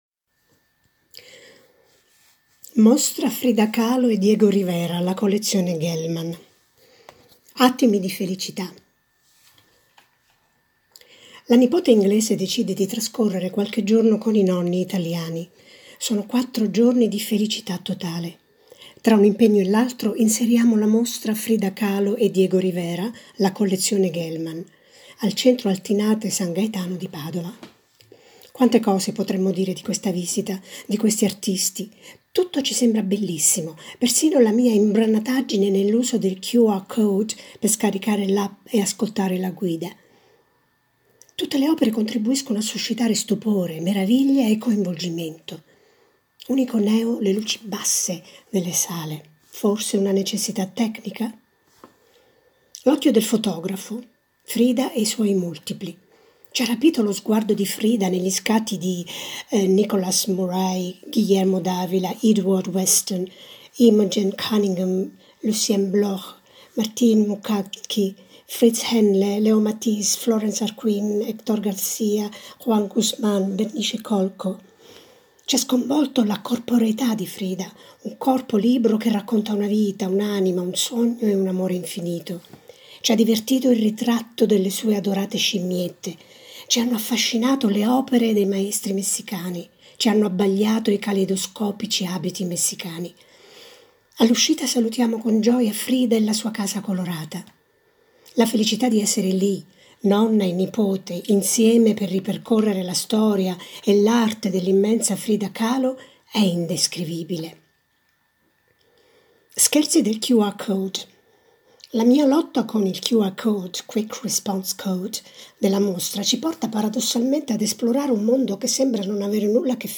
audiorecensione con cuore